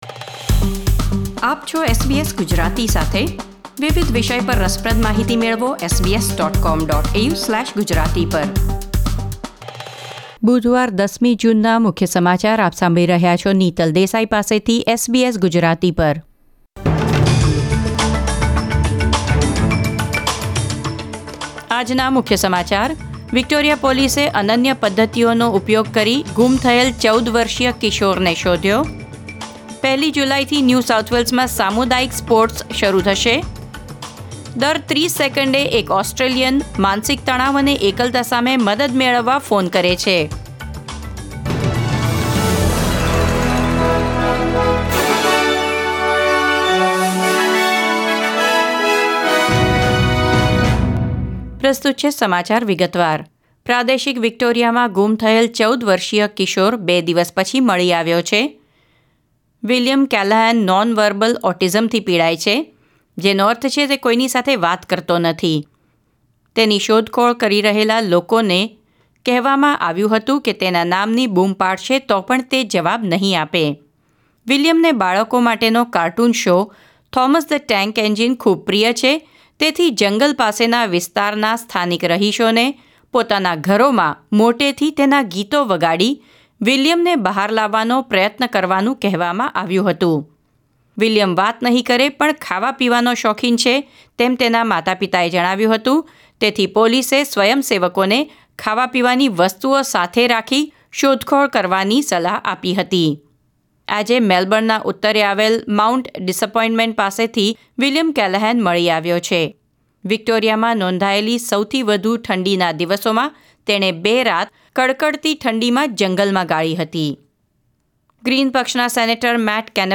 SBS Gujarati News Bulletin 10 June 2020